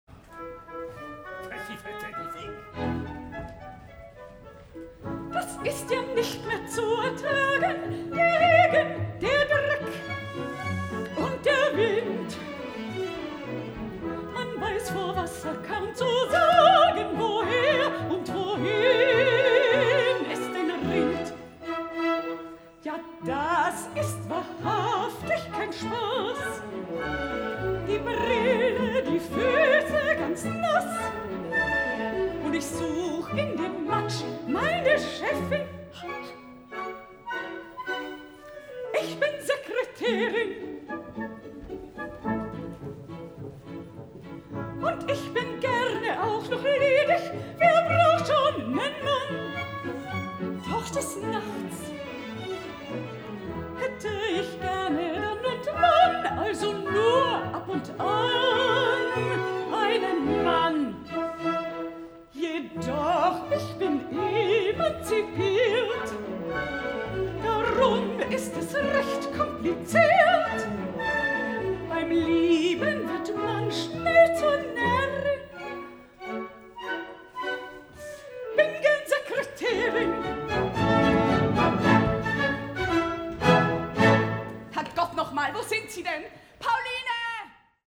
Auftrittscouplet Jeanne
Orchester des Staatstheaters am Gärtnerplatz